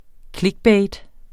Udtale [ ˈklegbεjd ]